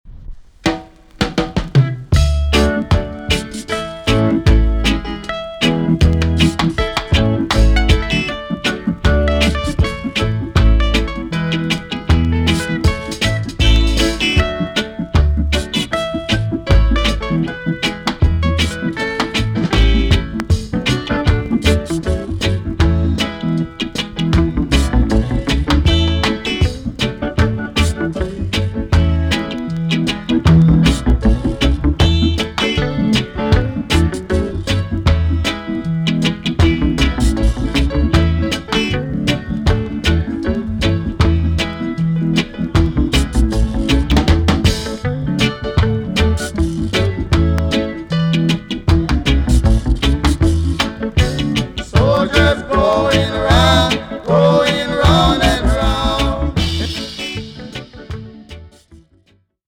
TOP >REGGAE & ROOTS
EX- 音はキレイです。
1973 , NICE VOCAL TUNE!!